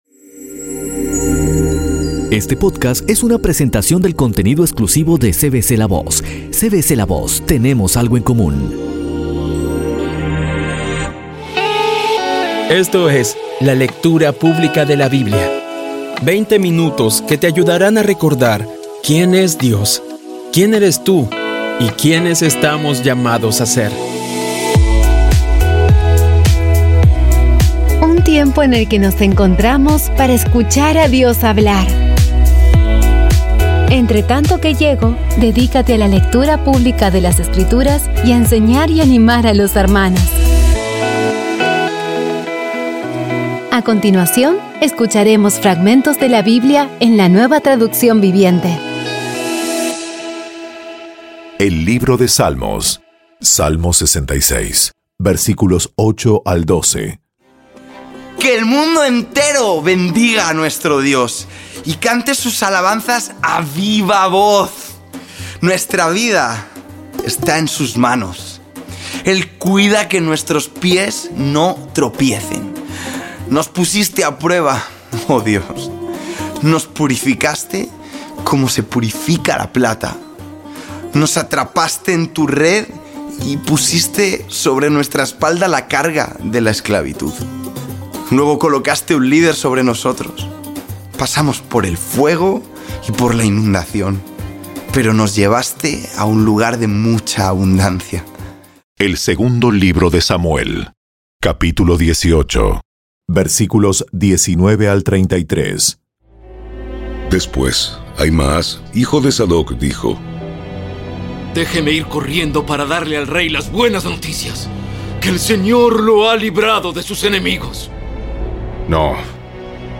Audio Biblia Dramatizada Episodio 145
Poco a poco y con las maravillosas voces actuadas de los protagonistas vas degustando las palabras de esa guía que Dios nos dio.